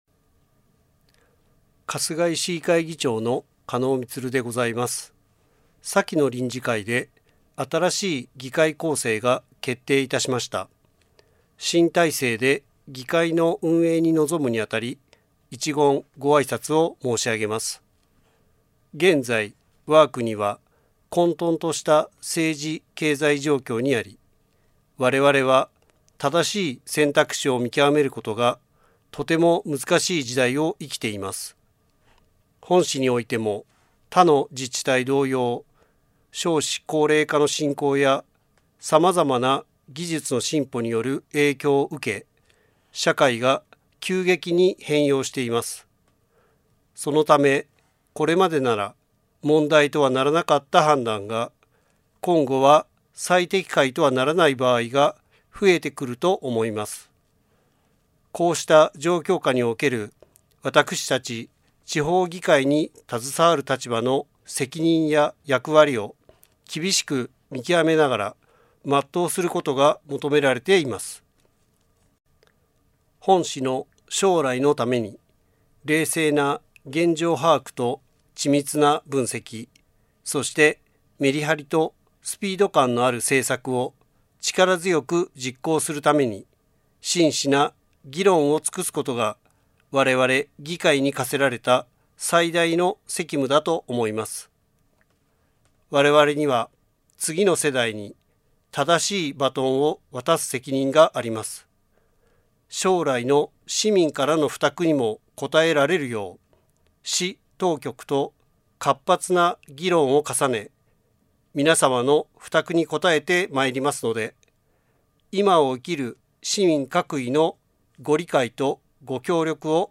議長のあいさつ